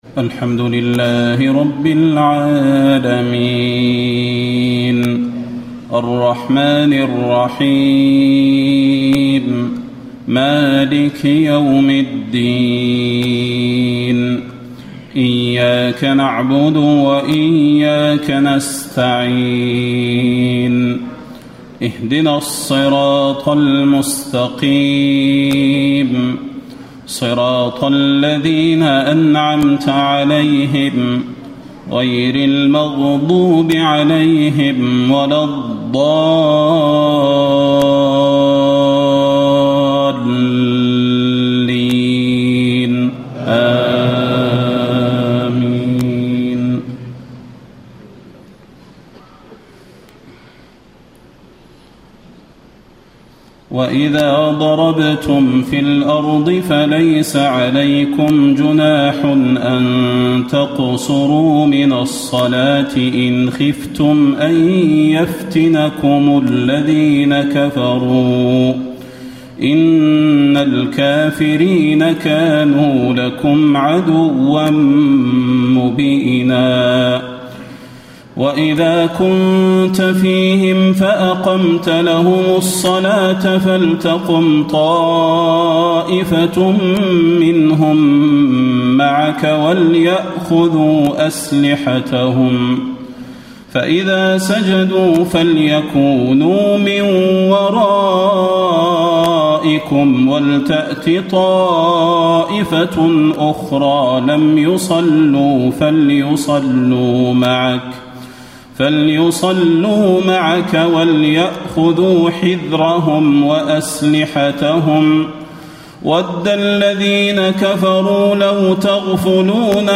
تهجد ليلة 25 رمضان 1435هـ من سورة النساء (101-147) Tahajjud 25 st night Ramadan 1435H from Surah An-Nisaa > تراويح الحرم النبوي عام 1435 🕌 > التراويح - تلاوات الحرمين